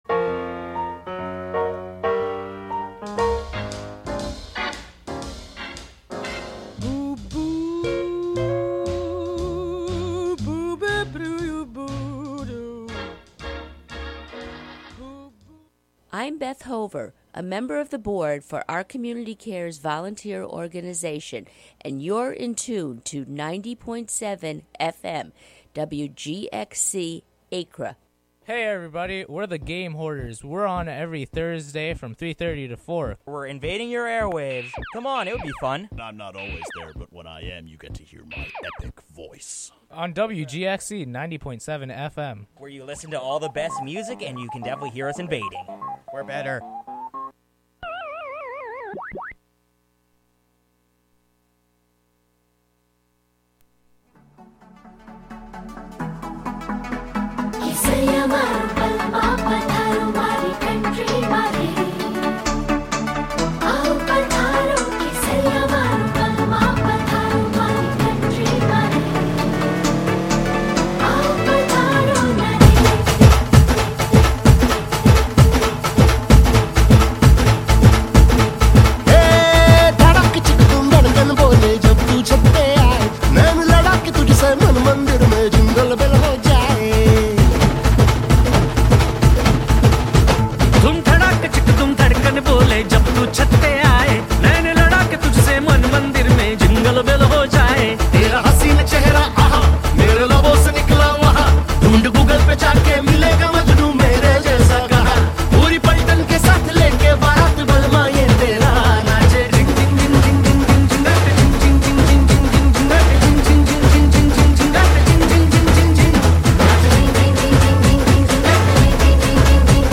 Youth Radio